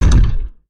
etfx_shoot_energy02.wav